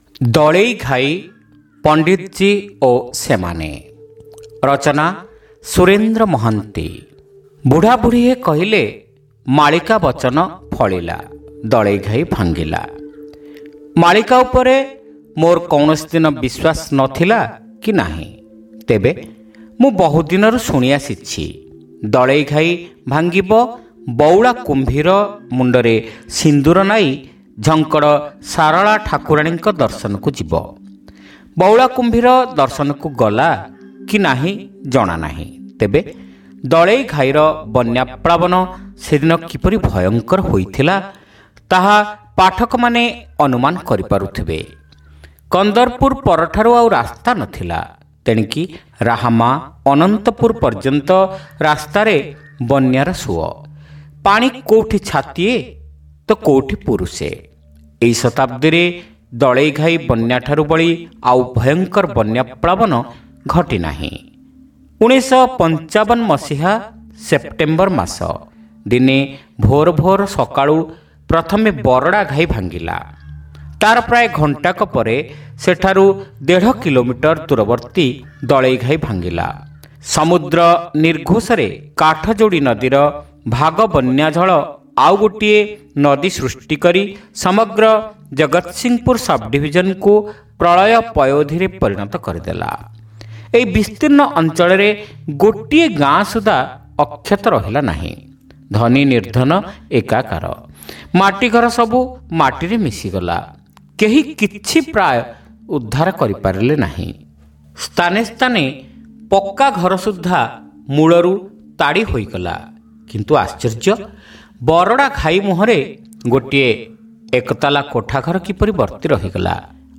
ଶ୍ରାବ୍ୟ ଗଳ୍ପ : ଦଳେଇଘାଇ ପଣ୍ଡିତଜି ଓ ସେମାନେ